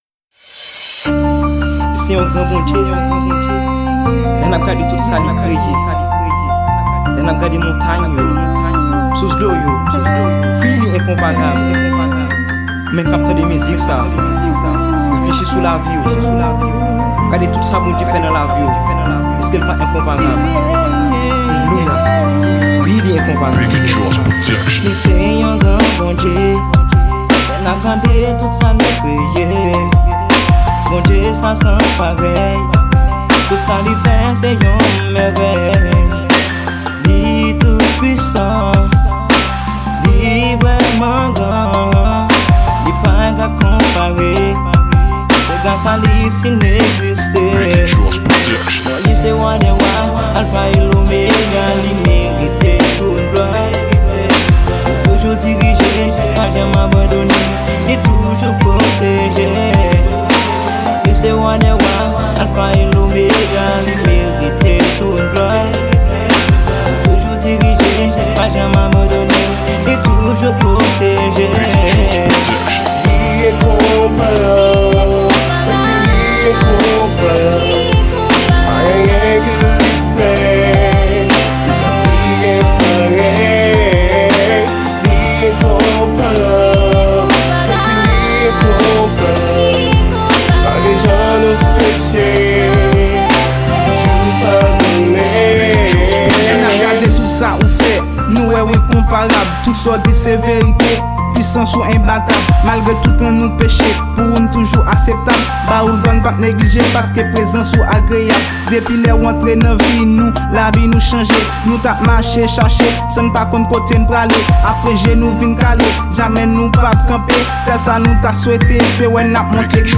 Genre: GOSPEL RAP.